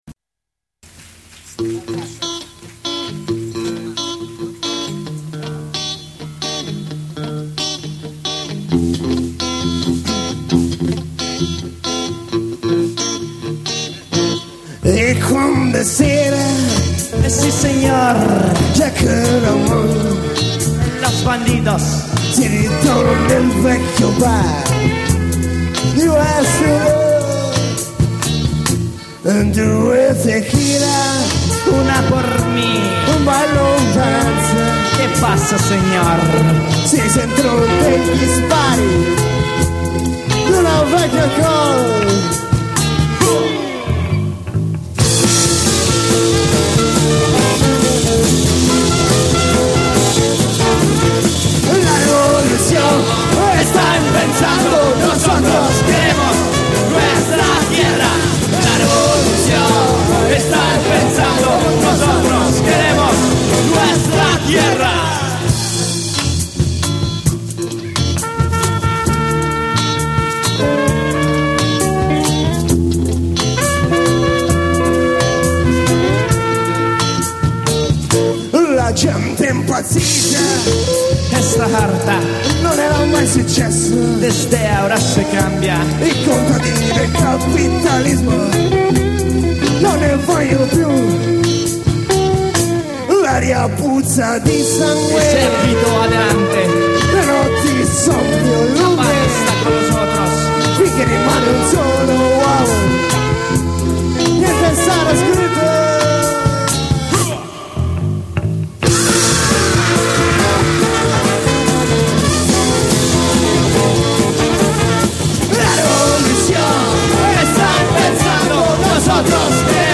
LIVE in Senigallia 1998